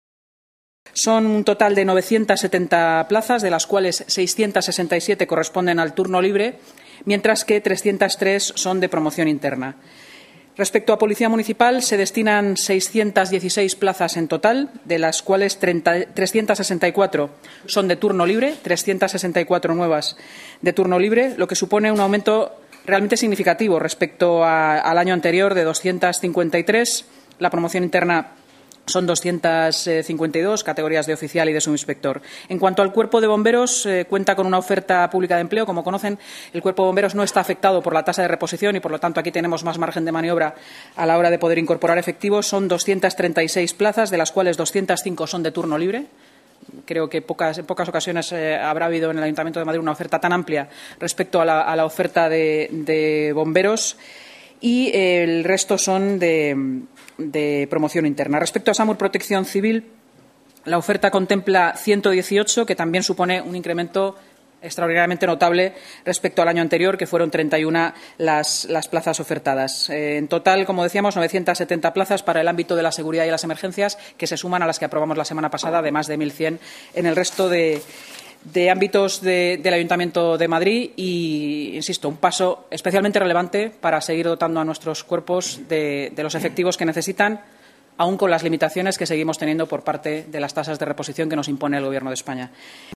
Nueva ventana:Intervención de la vicealcaldesa y delegada de Portavoz, Seguridad y Emergencias, Inma Sanz, en la rueda de prensa tras la Junta de Gobierno